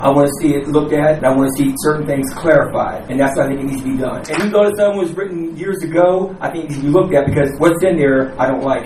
A rare crack in the Cumberland Mayor and City Council’s usual unity occurred during last night’s public meeting over ordinance 4019.
Last night, Councilman Eugene Frazier motioned to set aside 4019 again…